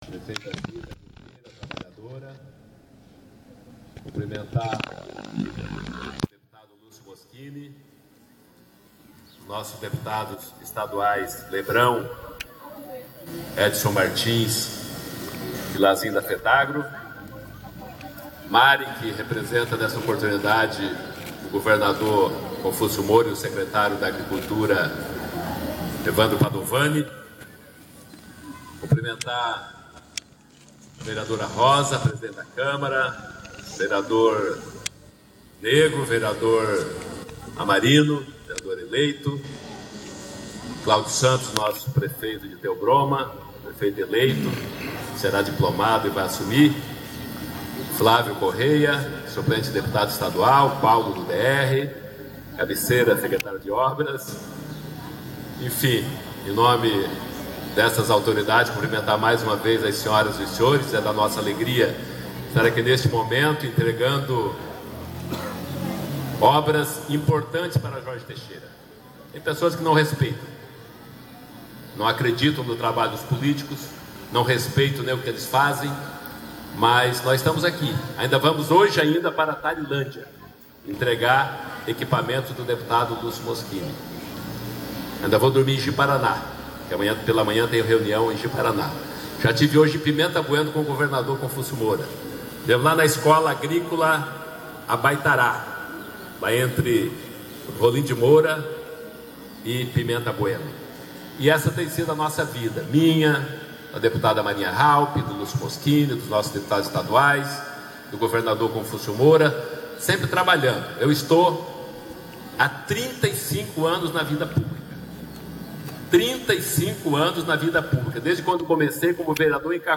O senador Valdir Raupp de matos, do PMDB de Rondônia, investigado pela operação Lava a jato por receber meio milhão de reais de propina do roubo da Petrobrás, numa atitude de autodefesa contra as acusações que pesam sobre suas costas, disse durante solenidade em Governador Jorge Teixeira que algumas pessoas não reconhecem e não respeitam o trabalho dos políticos do país, e que ele, pasmem os senhores, É FICHA LIMPA E PODE SER CANDIDATO A QUALQUER COISA QUE QUISER NO BRASIL.
Mesmo respondendo a (07) sete processos na justiça do País, o parlamantar disse em alto e bom som que tem o nome limpo.